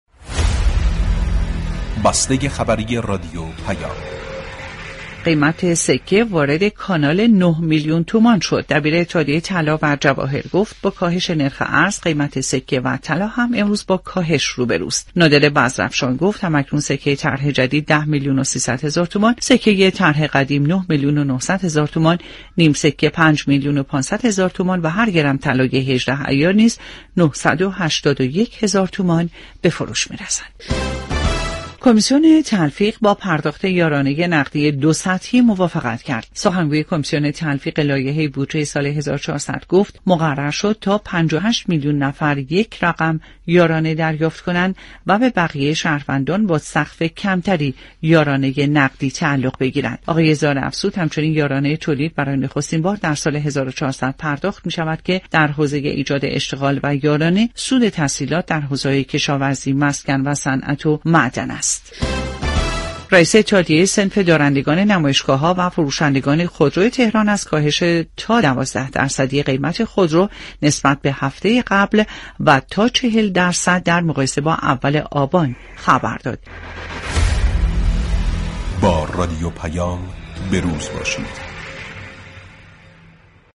بسته خبری صوتی رادیو پیام